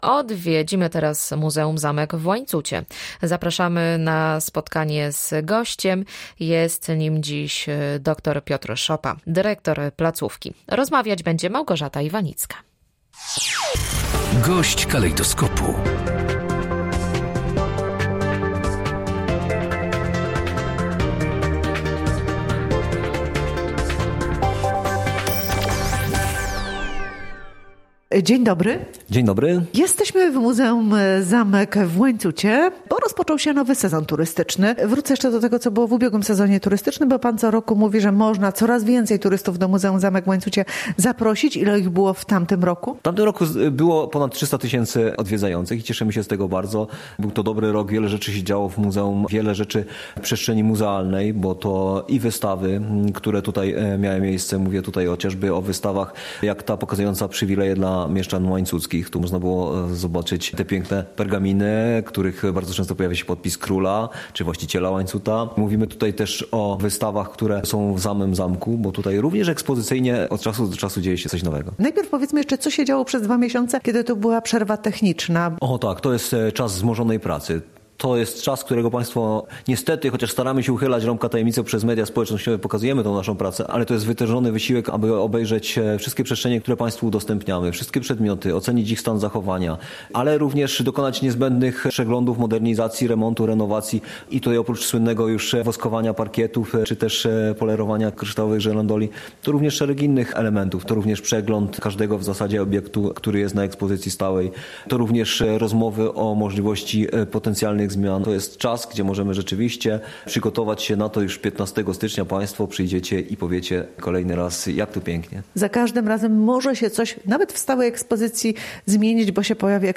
24_01_gosc_dnia.mp3